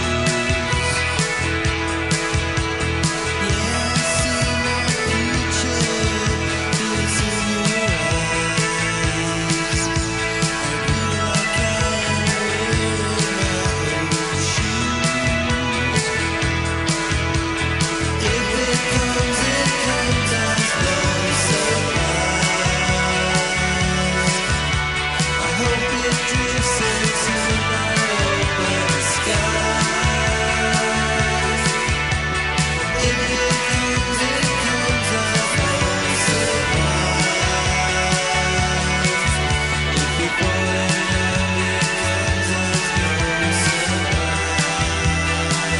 Recorded at La Maison (Bondi) except "At The Castle"